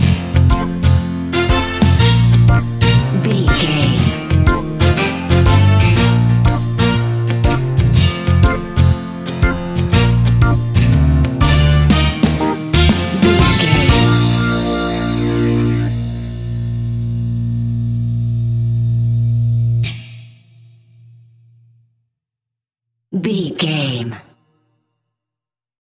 Hot summer sunshing reggae music for your next BBQ!
Ionian/Major
B♭
Slow
instrumentals
laid back
chilled
off beat
drums
skank guitar
hammond organ
percussion
horns